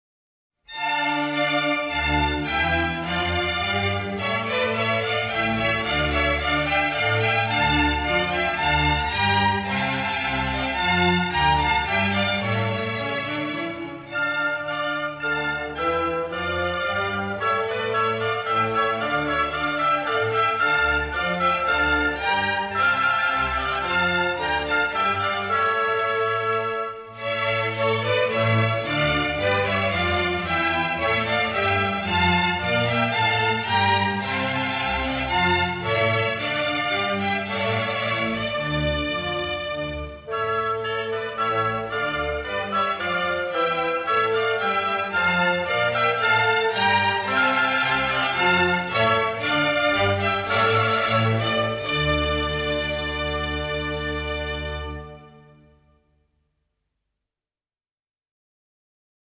Menuet